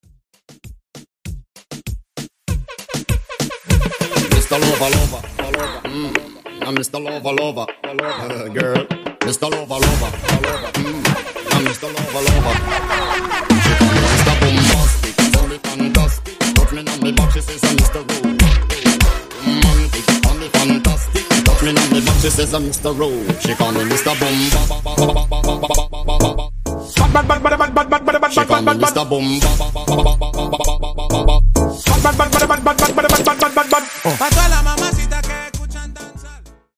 Intro Flow , Reggaeton